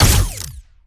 GUNAuto_Plasmid Machinegun B Single_06_SFRMS_SCIWPNS.wav